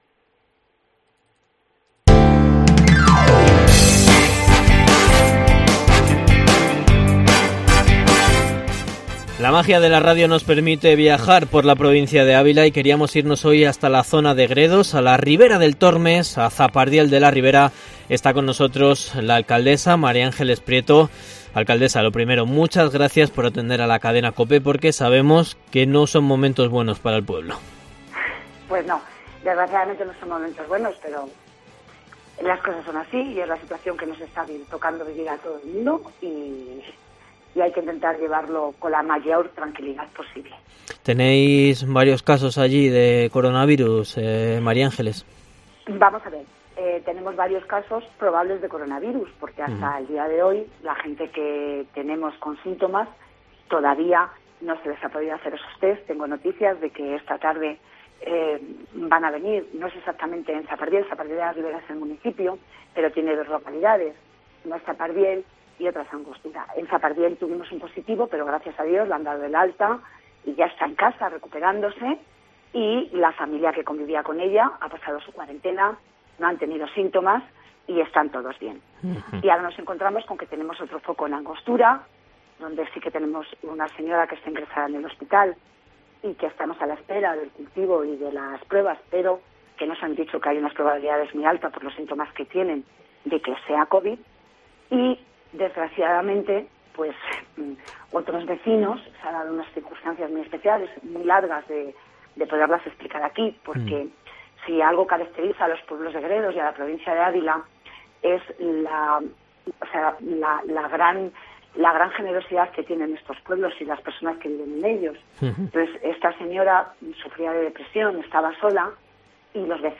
Entrevista a la alcaldesa de Zapardiel de la Ribera, Mª Ángeles Prieto, en COPE Ávila